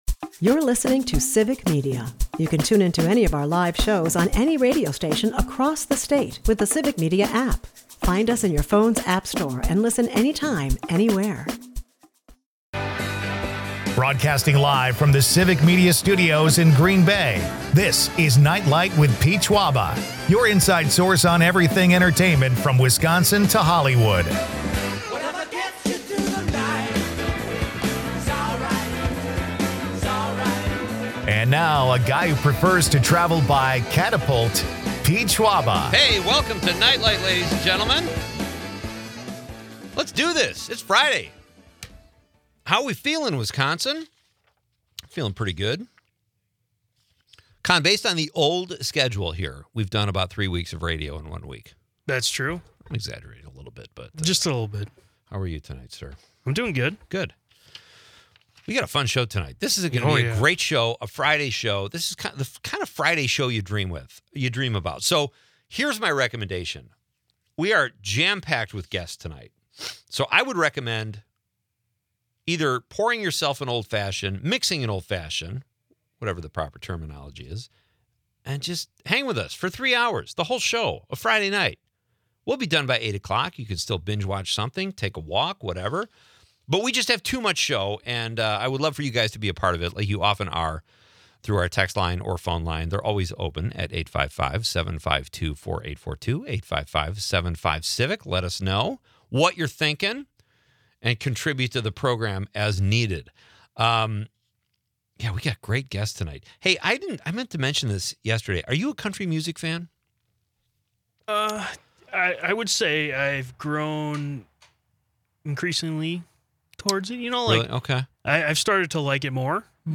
The episode promises a mix of humor, music, and Wisconsin charm, topped with a question on favorite sad songs.